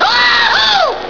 wahoo.wav